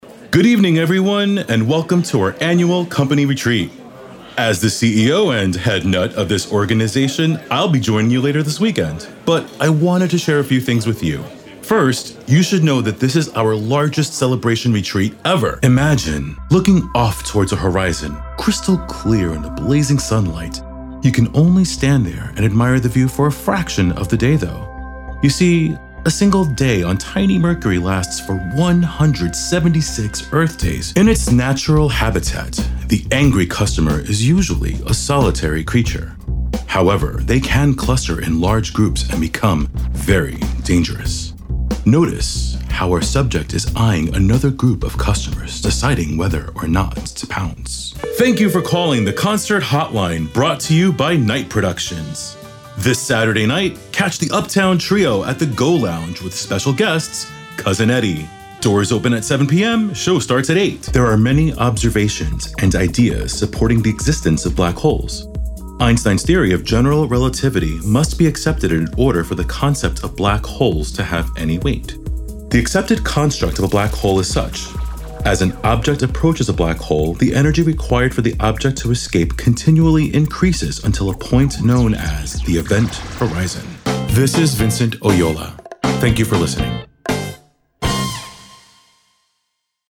I am an adult male, voice range 30-55.
Narrative Demo
Young Adult
Middle Aged